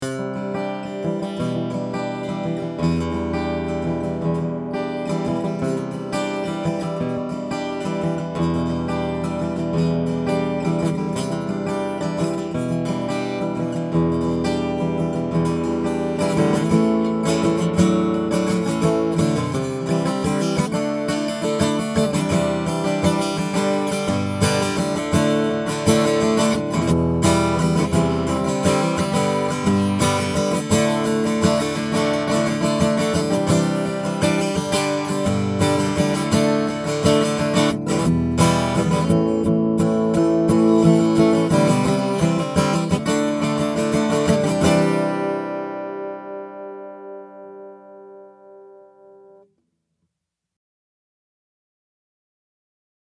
All of the clips are with the guitar plugged directly into my pre-amp going into my DAW.
I recorded the individual images with the image mix cranked all the way up.